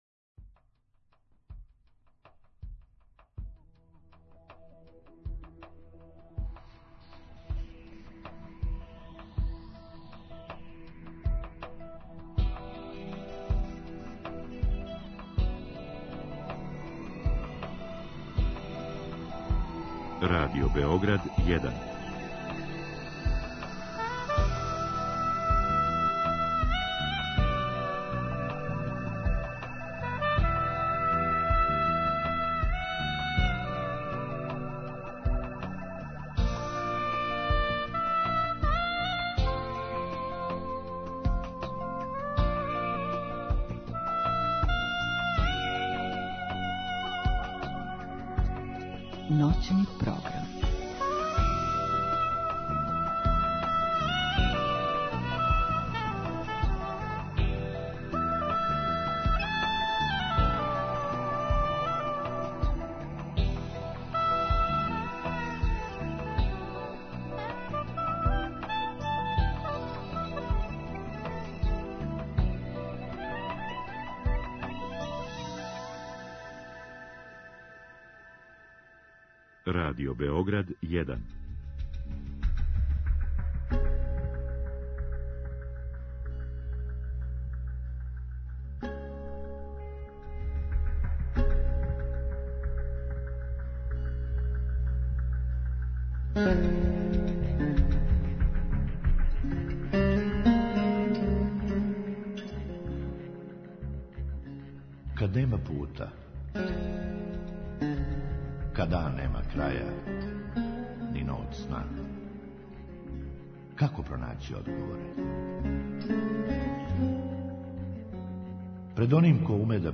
То ћемо, између осталог, сазнати у ноћашњем разговору о телесној психотерапији.
У другом сату слушаоци имају прилику да у директном програму поставе питања нашој гошћи.